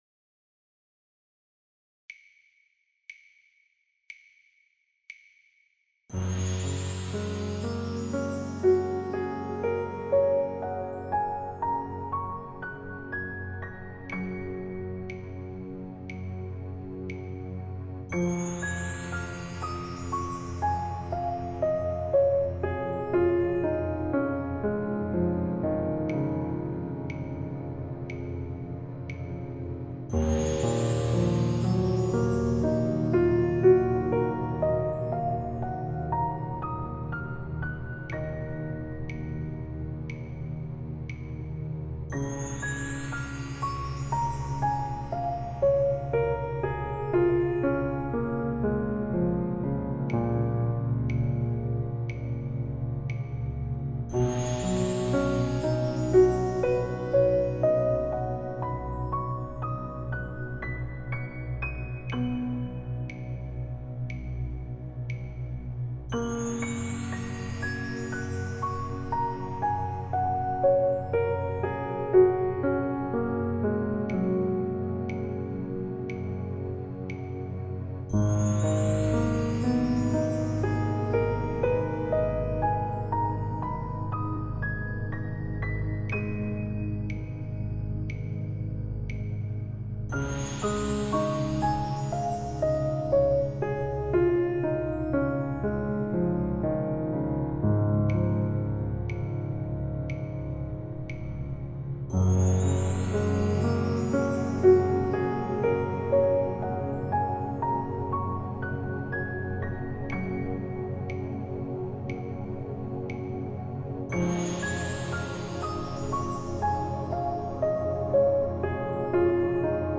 • Accord ascendant pour l’inspiration
• Accord maintenu + 4 coups de baguette pour les rétentions
• Accord descendant pour l’expiration
• Première moitié : Alpha (concentration, lucidité)
• Deuxième moitié : Thêta (méditation, rêve, sortie astrale)